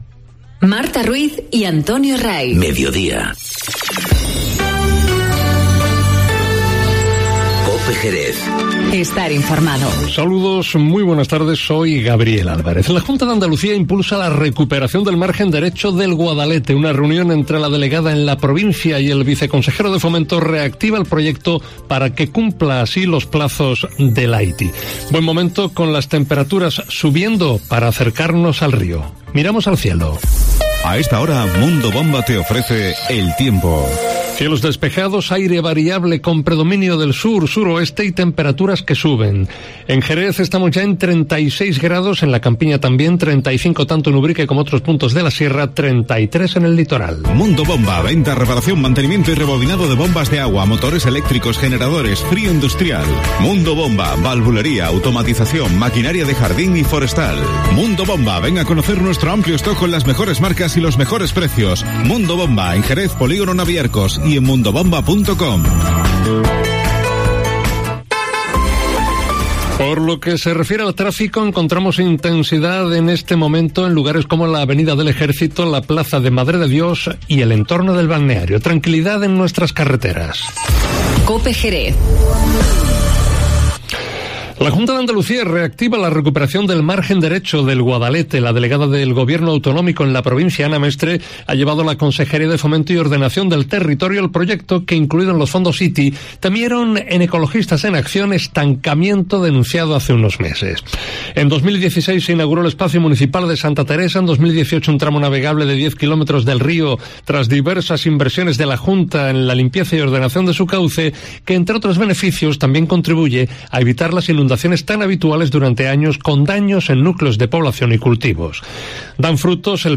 Informativo Mediodía COPE en Jerez 19-07-19